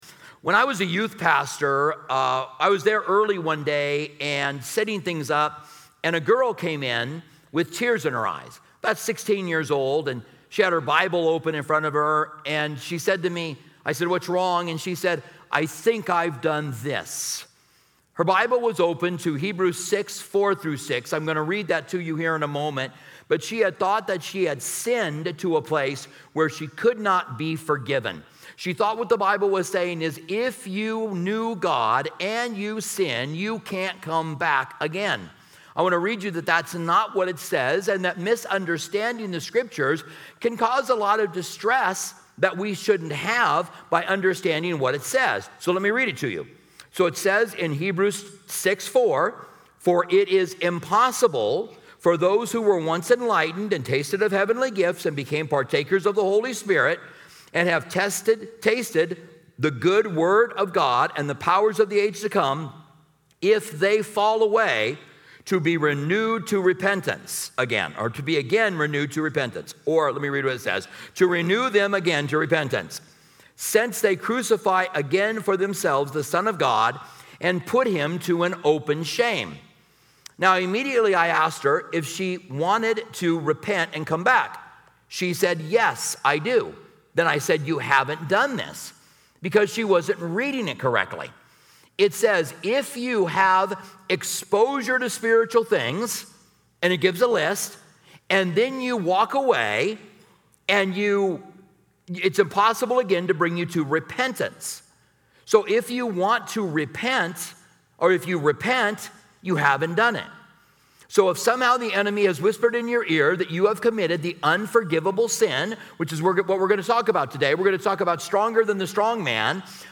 This sermon is designed for anyone seeking deeper understanding and guidance in their faith journey.